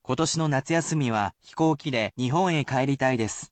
And, I will speak aloud the sentence example.